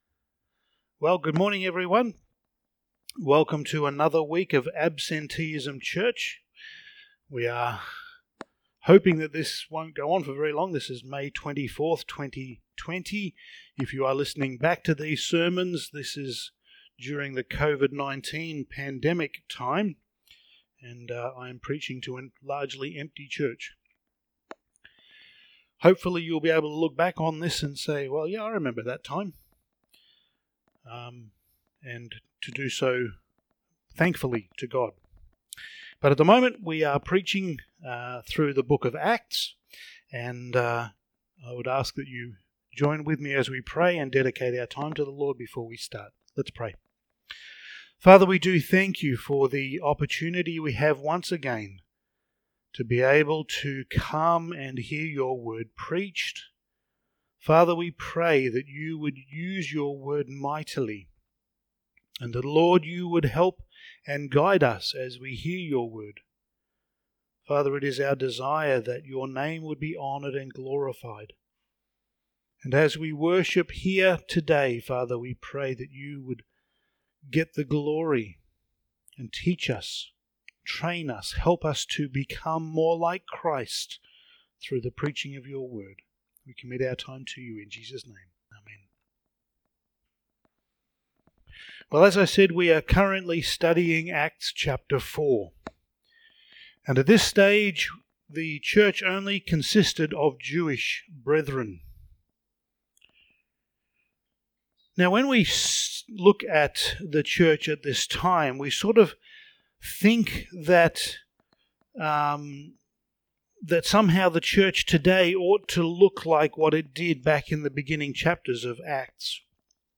Passage: Acts 4:32-5:11 Service Type: Sunday Morning